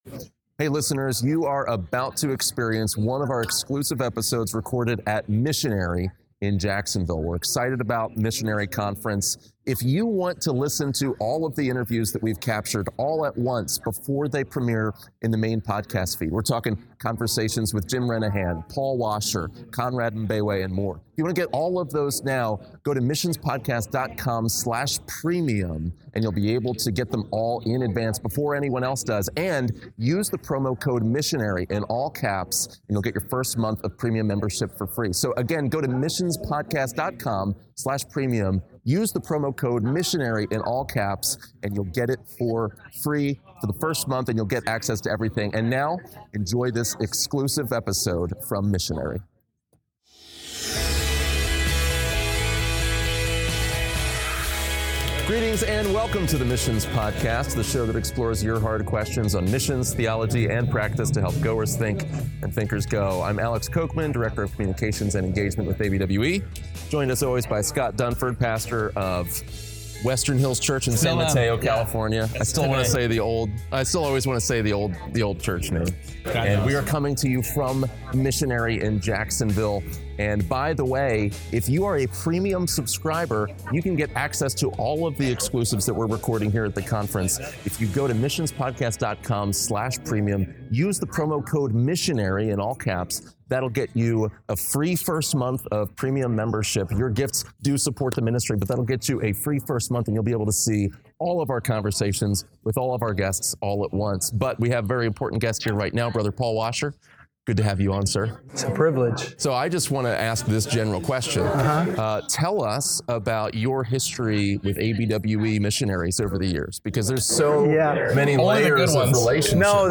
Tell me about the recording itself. What kind of men do we need going to the mission field? In this exclusive interview from Missionary Conference